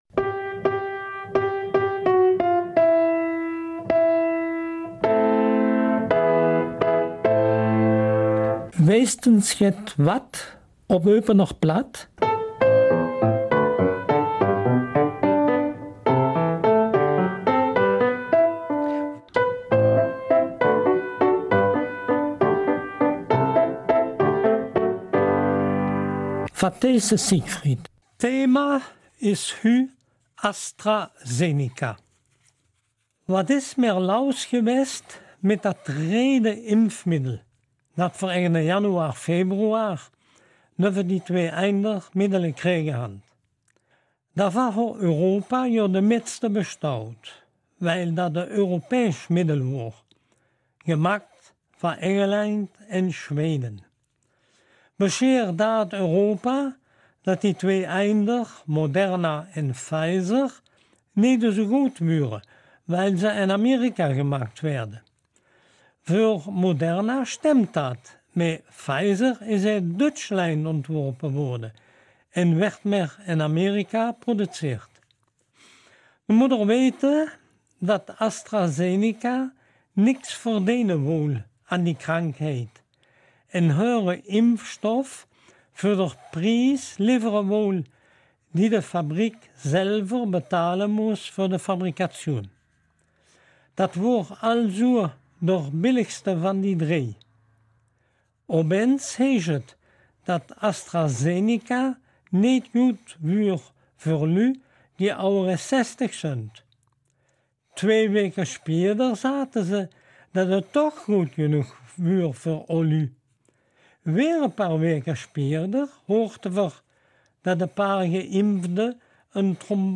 Eupener Mundart: ''Astra Zenica“